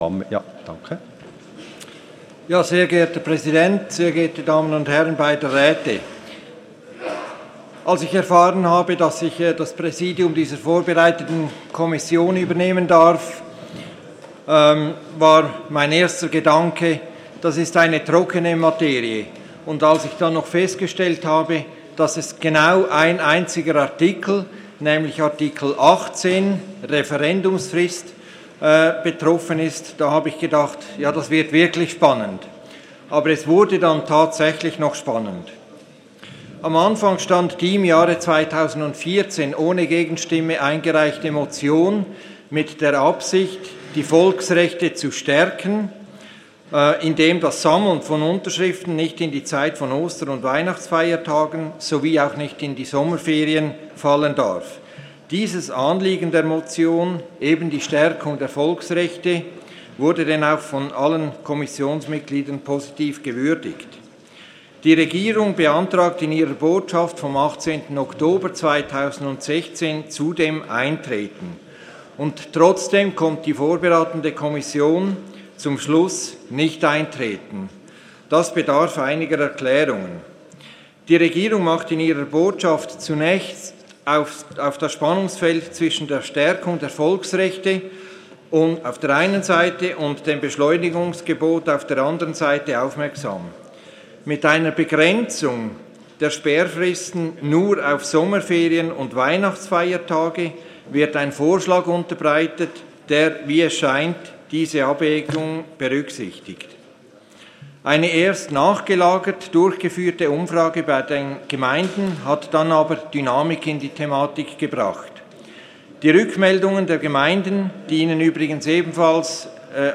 Session des Kantonsrates vom 20. und 21. Februar 2017